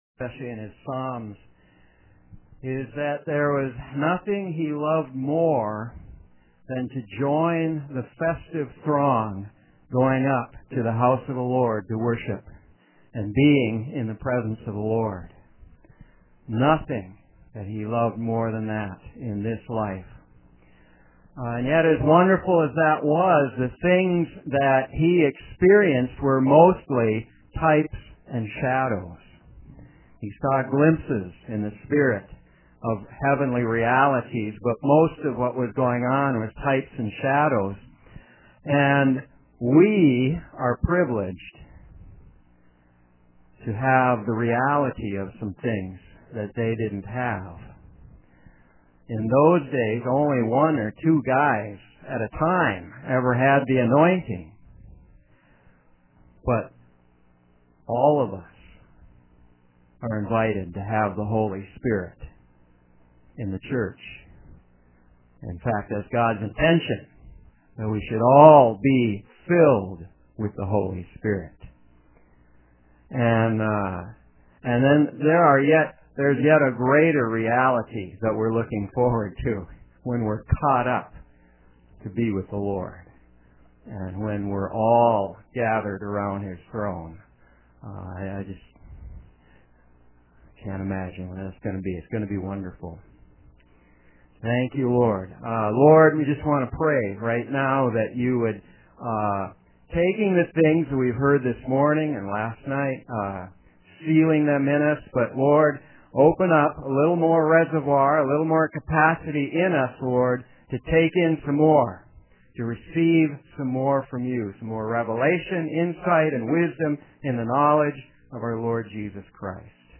2012 Prophetic Conference Session 3